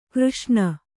♪ křṣṇa